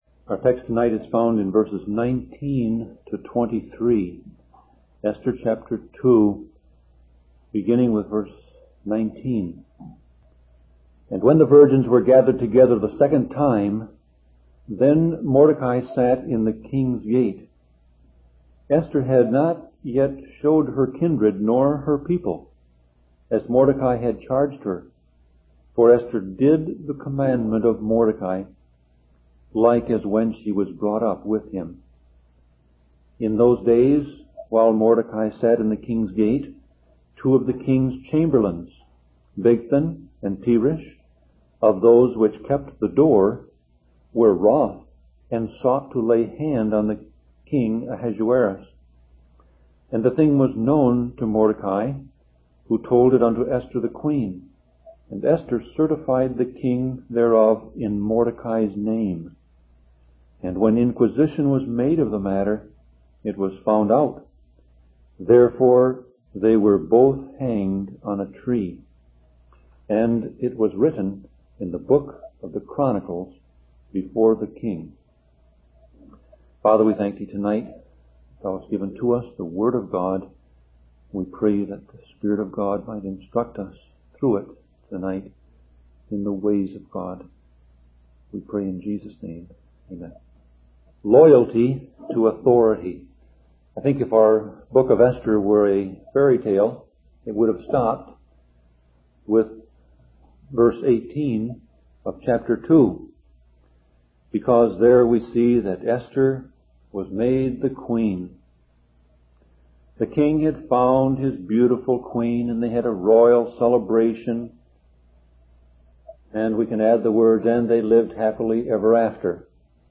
Series: Sermon Audio Passage: Esther 2:19-23 Service Type